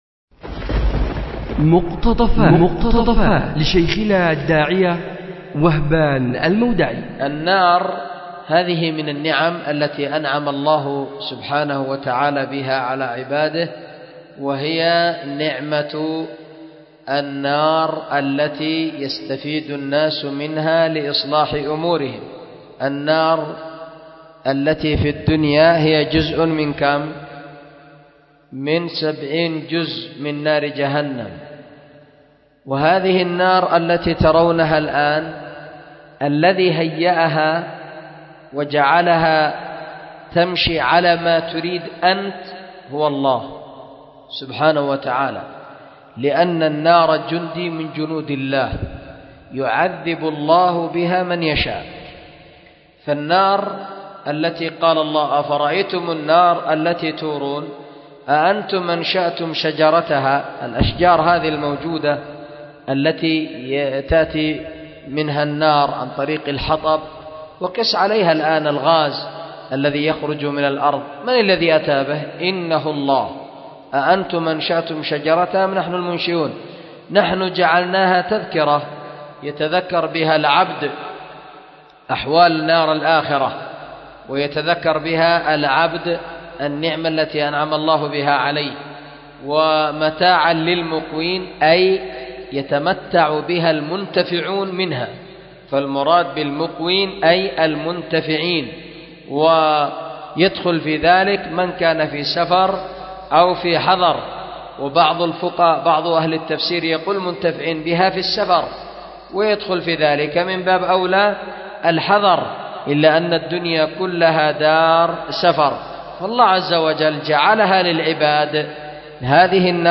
مقتطف من درس
أُلقي بدار الحديث للعلوم الشرعية بمسجد ذي النورين ـ اليمن ـ ذمار